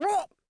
Cat Pain Sound Effect
Download a high-quality cat pain sound effect.
cat-pain-4.mp3